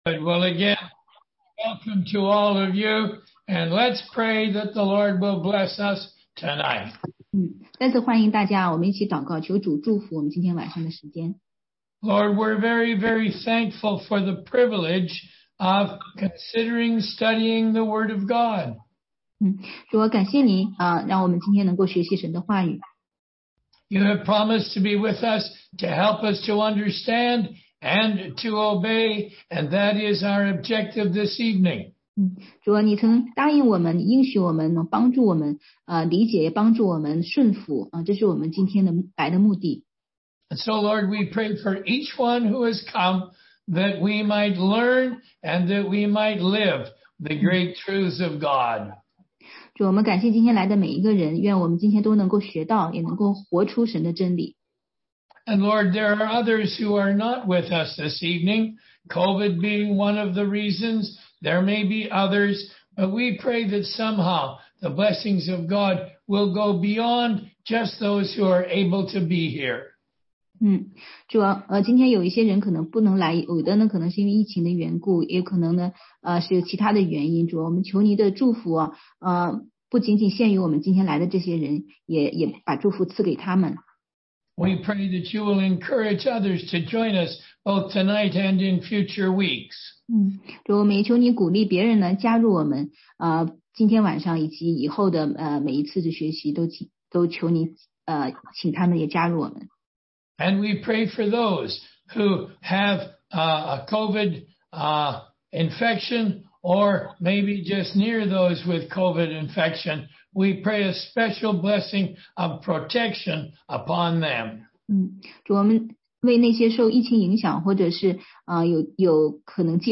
16街讲道录音 - 读经的方法和原则系列之十一：新约对旧约法律判例的使用